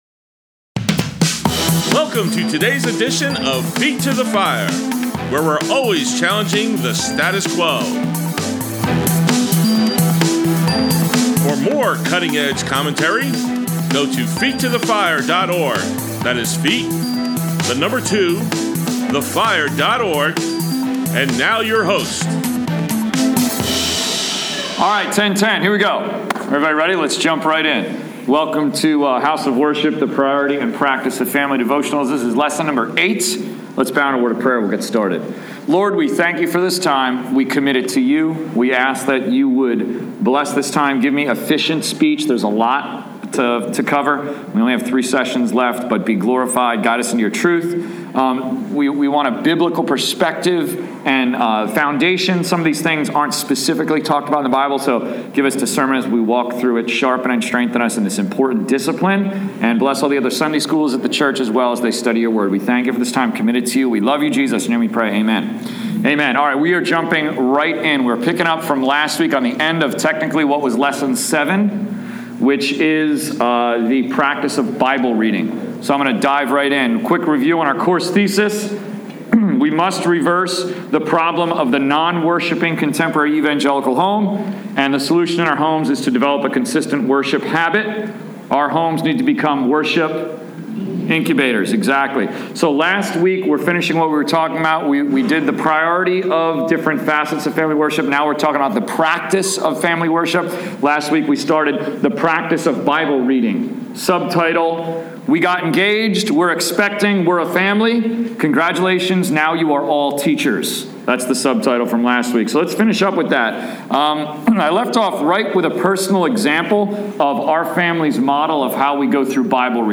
Grace Bible Church, Adult Sunday School, 3/6/16